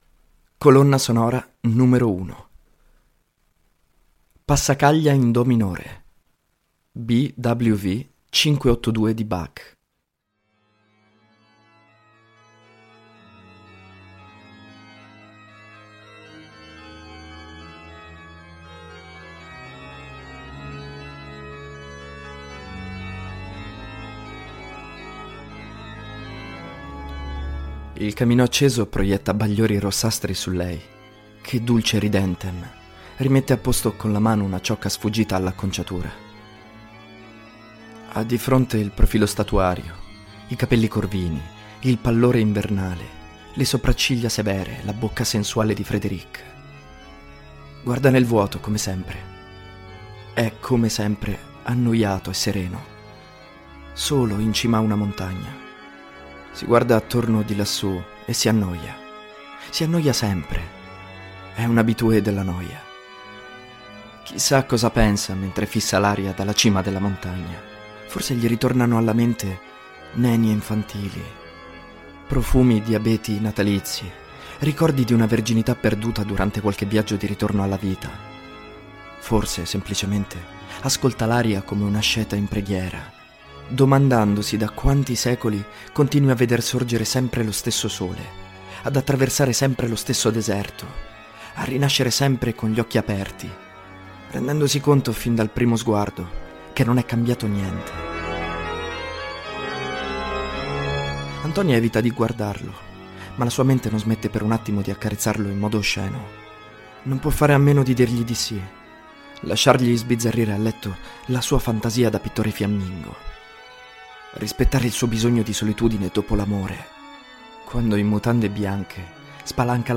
La colonna sonora è la "Passacaglia in Do minore" di J.S. Bach.
The soundtrack is "Passacaglia in C minor" by J.S. Bach.